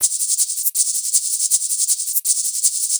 Shaker 07.wav